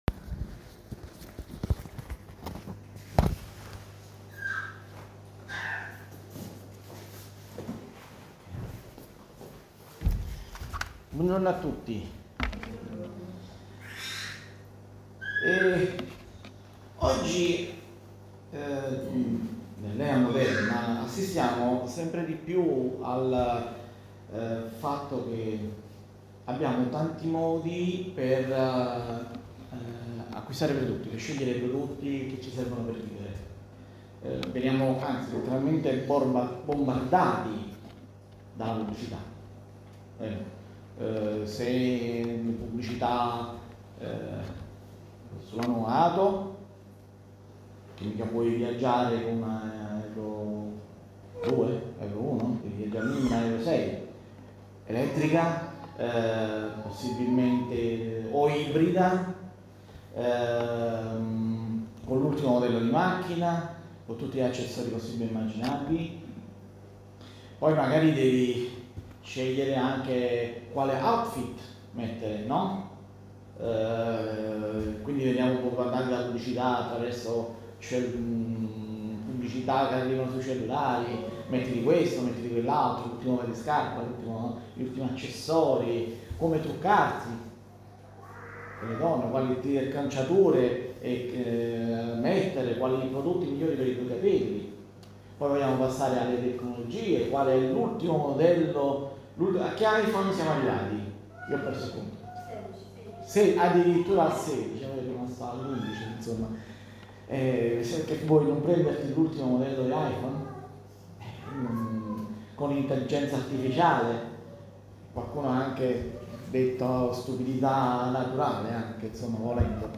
Insegnamenti biblici sul passo di Isaia 55:1-6.